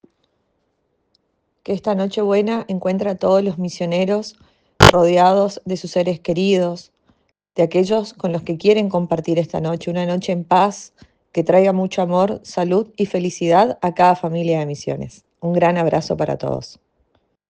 La Diputada Provincial Paula Franco envió un cálido saludo a todas las familias misioneras - Agencia de Noticias Guacurari